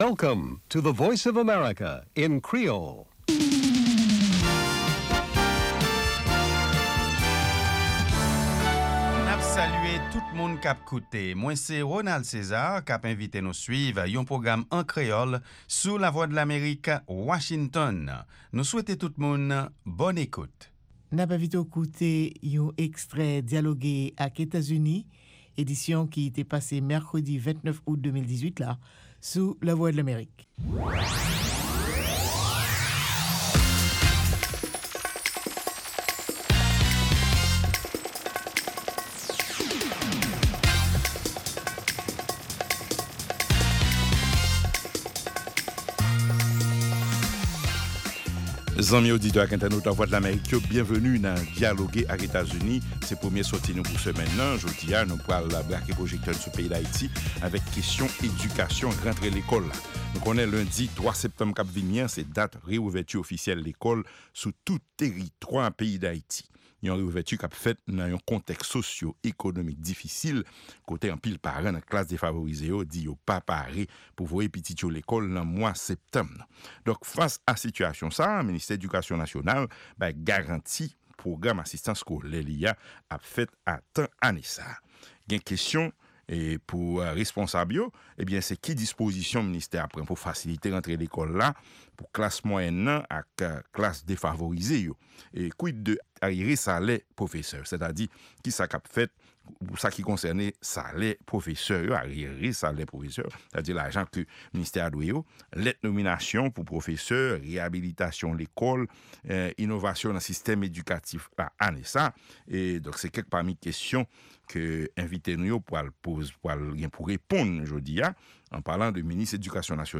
Se 3èm e dènye pwogram jounen an, avèk nouvèl tou nèf sou Lèzetazini, Ayiti ak rès mond la. Pami segman ki pase ladan yo e ki pa nan lòt pwogram yo, genyen Lavi Ozetazini ak Nouvèl sou Vedèt yo.